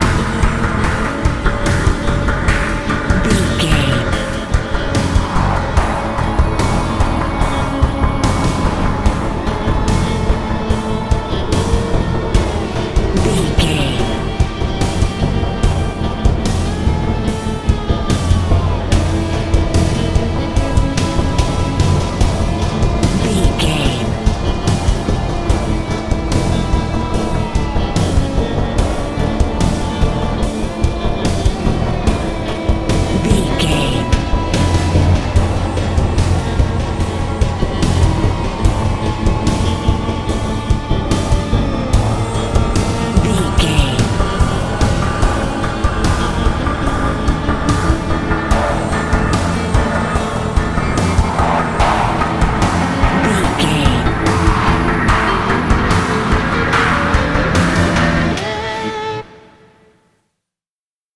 Thriller
Aeolian/Minor
synthesiser
drum machine
electric guitar